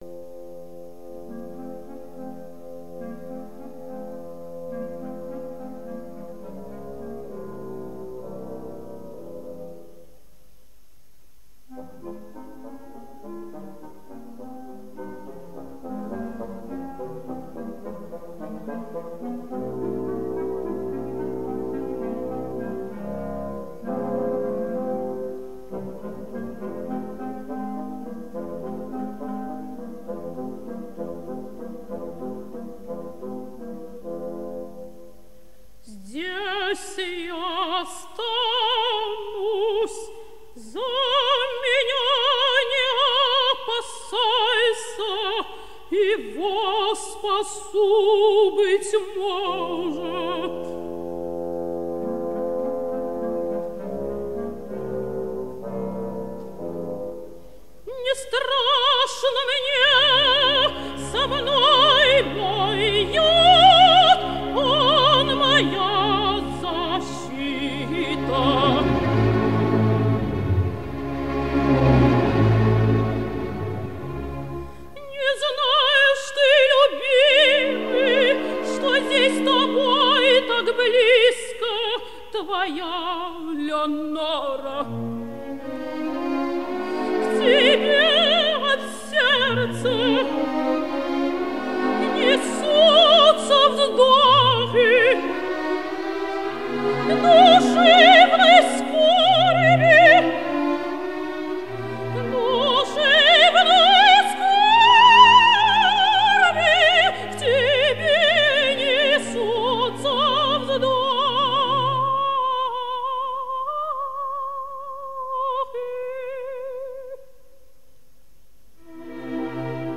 Обладала гибким выразительным голосом (лирическое сопрано), «ровным и наполненно звучащим во всех регистрах, на редкость серебристого тембра», великолепной филировкой ( С. 90), драматическим темпераментом.
Исполняет М. П. Амиранашвили.